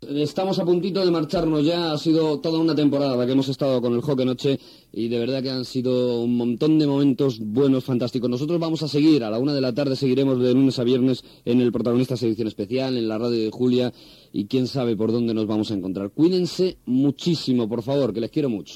Comiat del programa de final de temporada.
Entreteniment